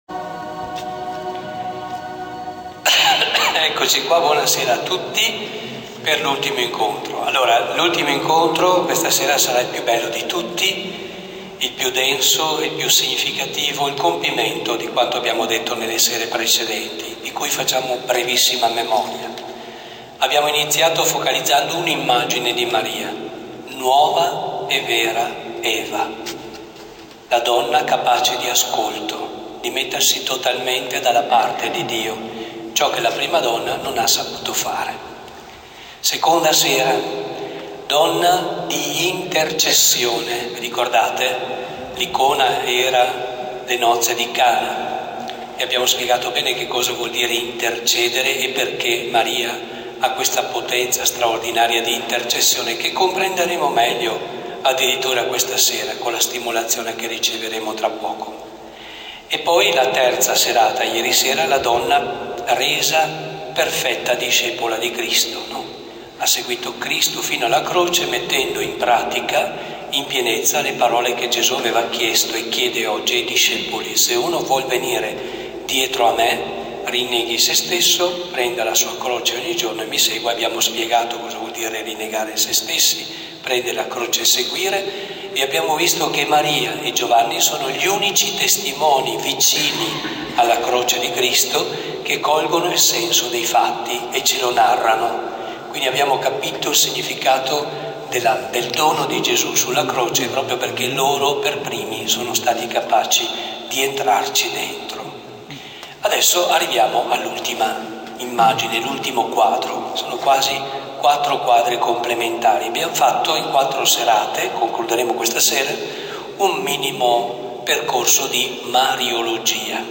Esercizi spirituali parrocchiali